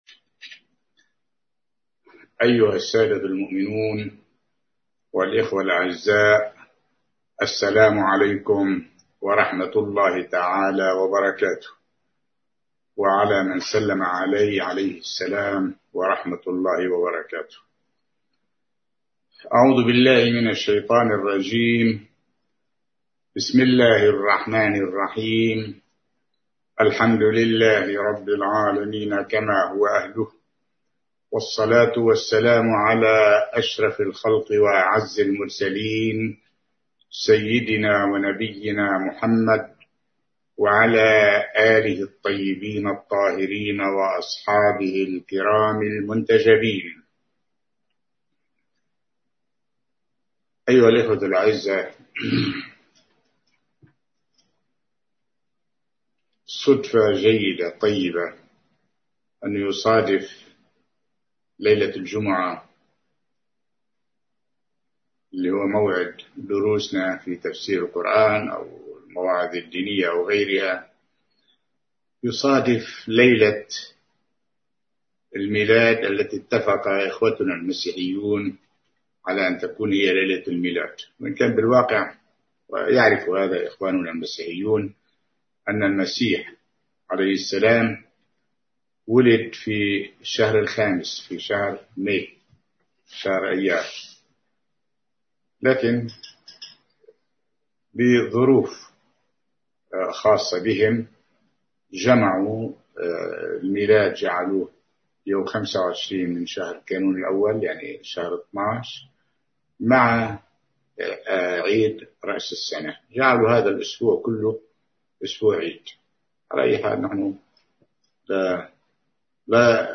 محاضرة
ألقاها في السنغال